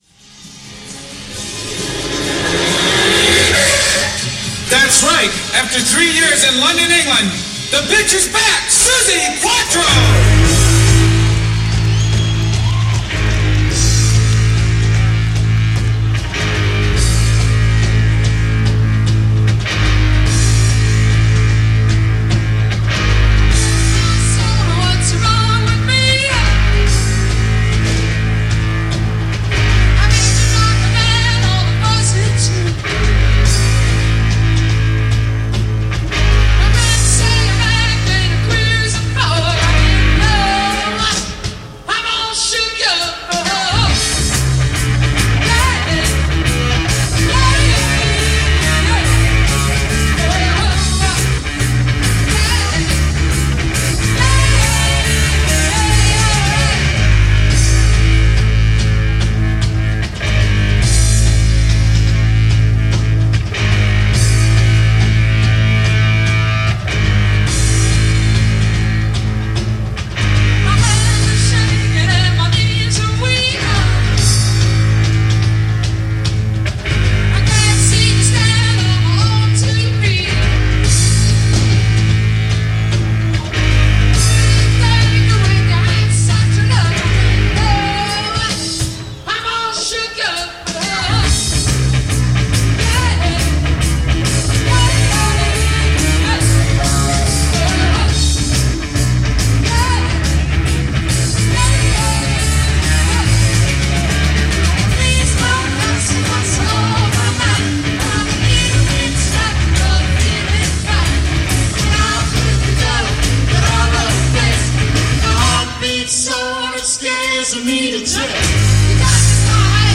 70s Glam
a leather-clad bass player